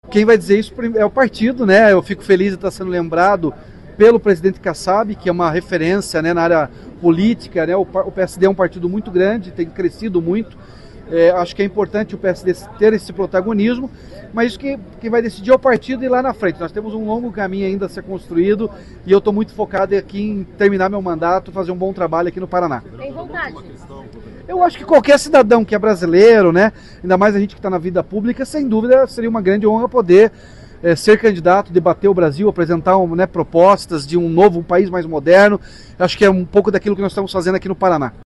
SONORA-RATINHO-CANDIDATO-01-JC.mp3